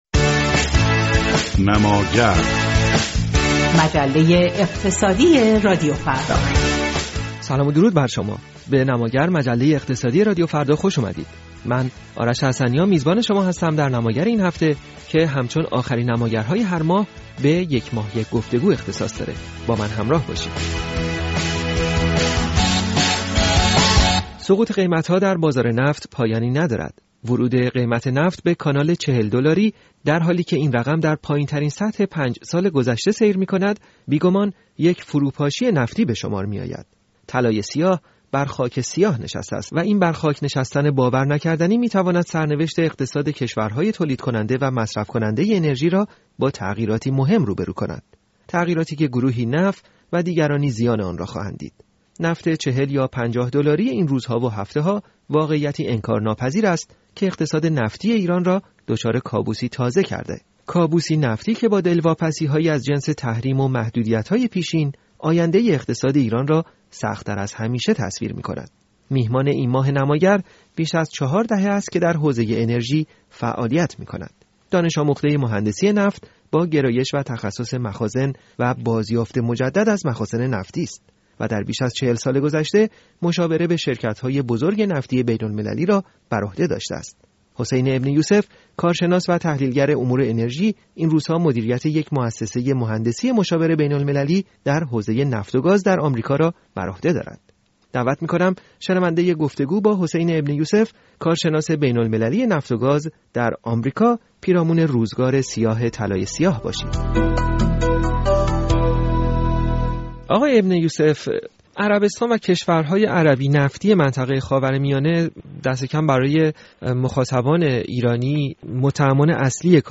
برنامه رادیویی نماگر